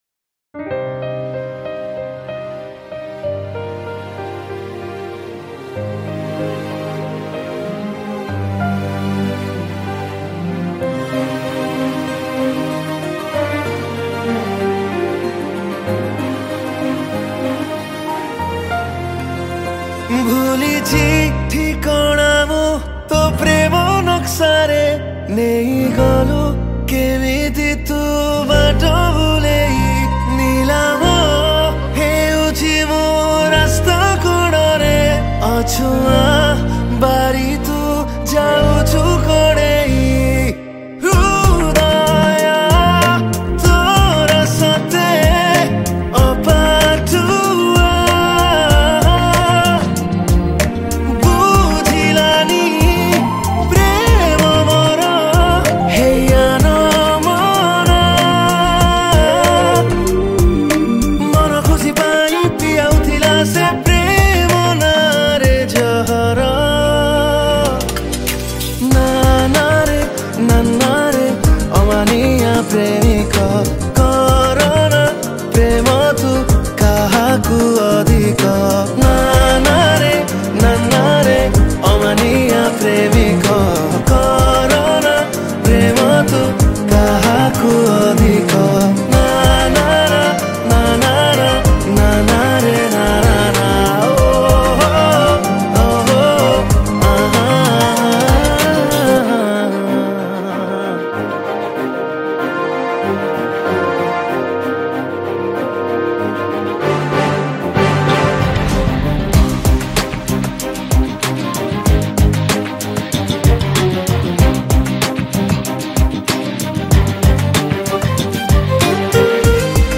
Keyboard
Chorus
Guitar